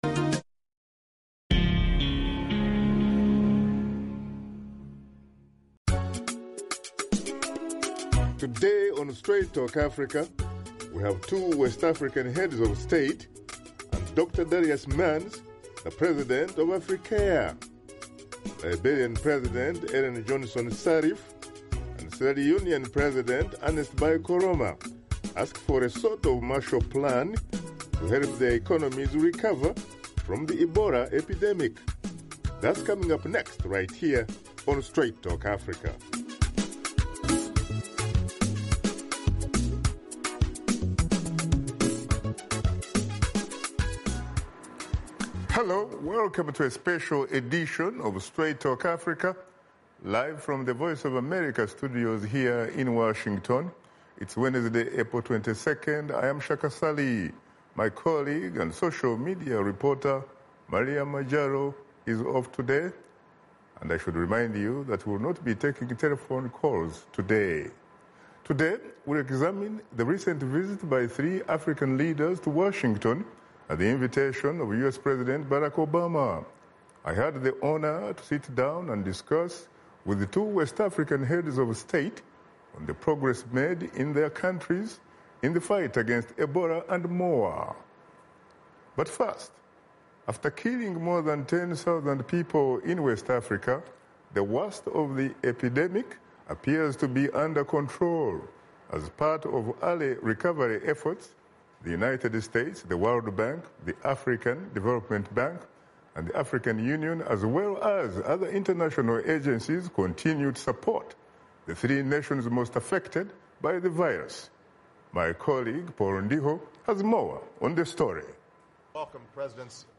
Host Shaka Ssali brings you in-depth interviews with two West Africa leaders who's nation was most affected by the Ebola epidemic to assess the progress and talk about recovery efforts with U.S President Barack Obama.